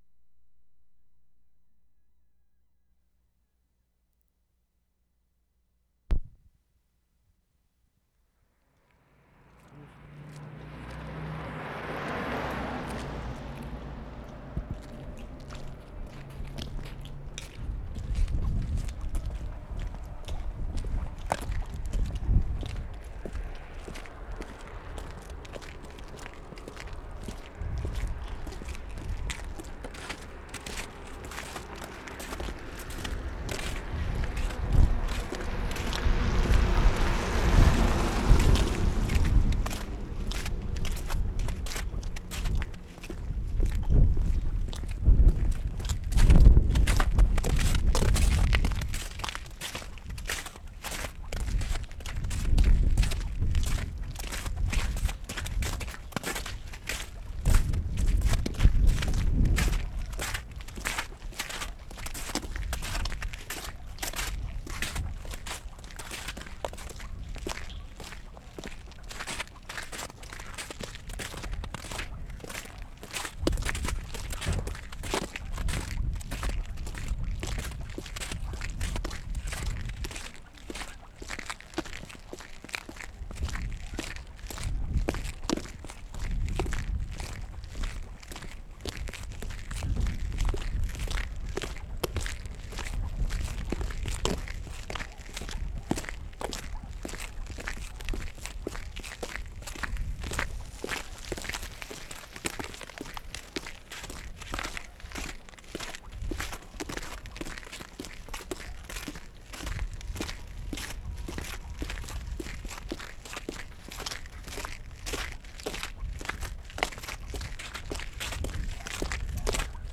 Skruv, Sweden Feb. 18/75
STREET AMBIENCE ON STORGATAN
mark * siren from glassworks factory [2:52]
6. Lots of wind in mic.; footsteps of the recordist, barking dog somebody passing with radio.